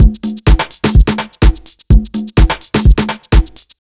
126BEAT1 1-L.wav